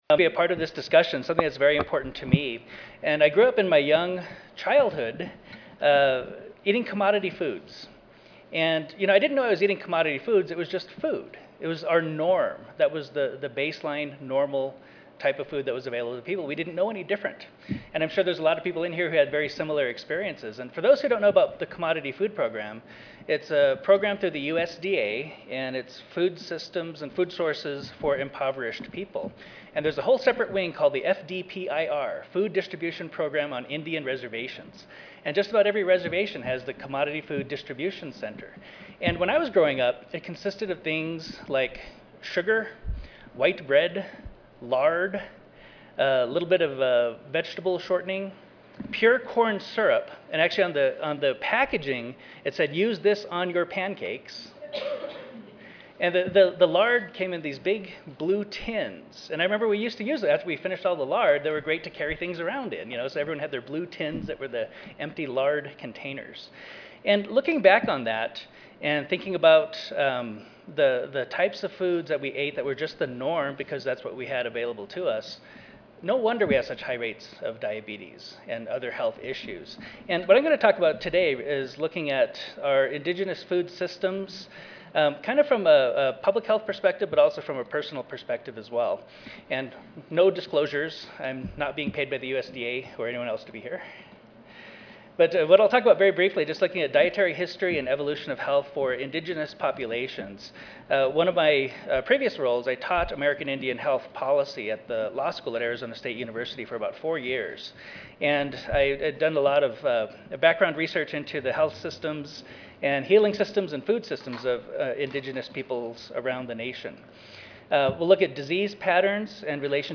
3394.0 Rebuilding indigenous food systems Monday, November 8, 2010: 4:30 PM - 6:00 PM Oral American Indians are taking control of their food systems, despite some seemingly incredibly long odds.
The speakers include: a writer, documentary filmmaker, and former bison rancher from South Dakota; a producer who raises sheep and is a leader in western sustainable/organic agriculture efforts; a physician/advocate; and an official of the First Nation Development Institute, which teaches tribes to do assessments of food sovereignty.